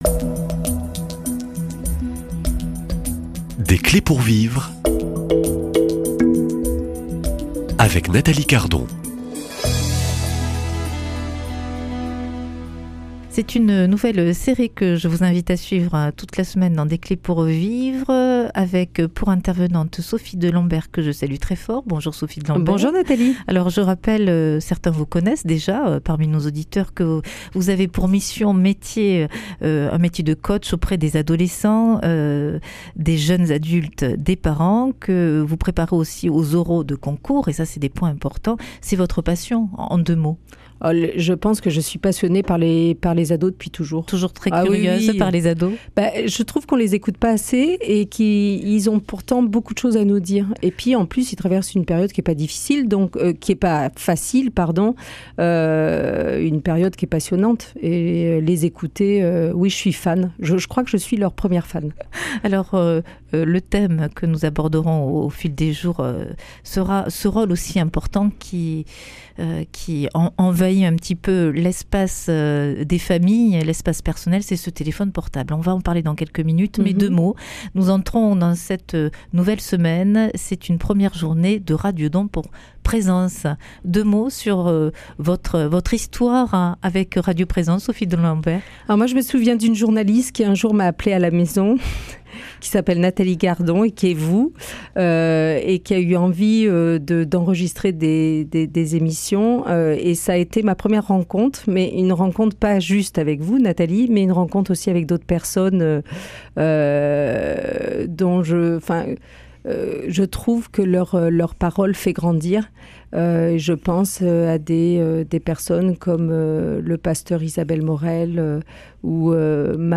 Invitée de la semaine